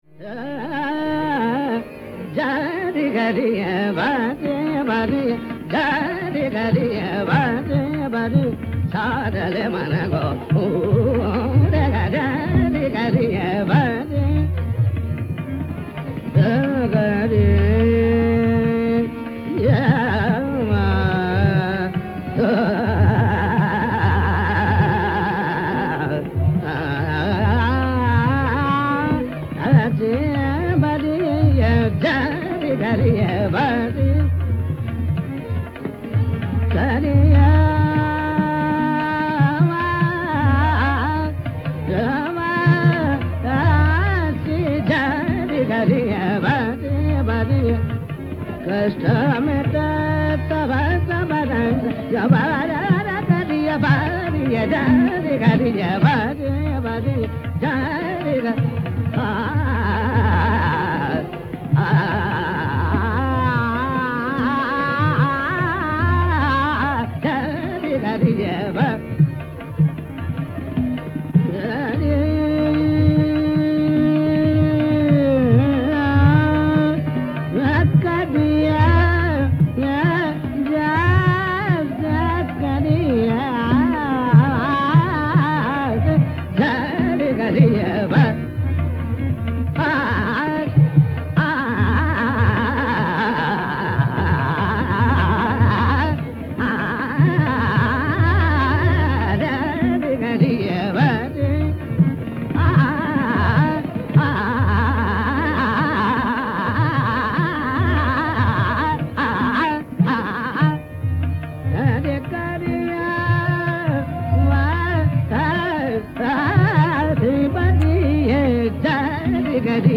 Raga Adana